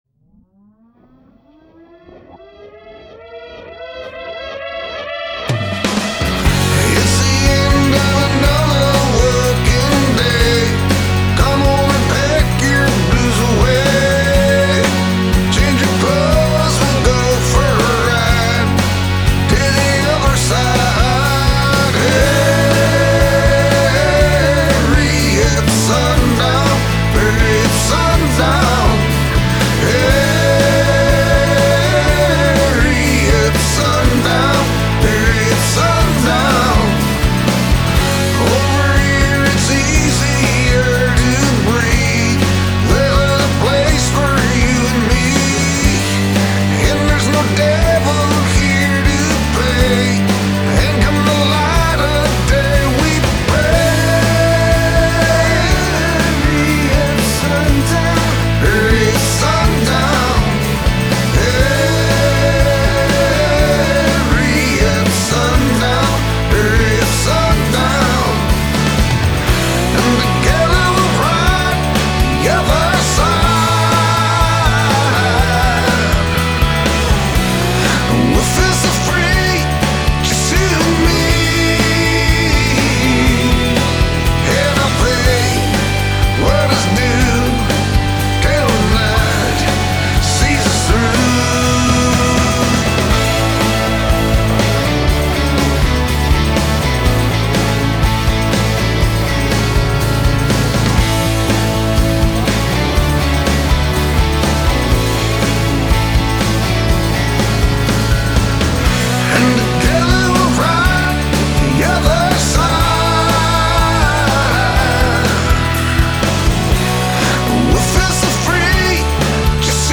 ” particularly with its carmelized, fattened-up vocal track.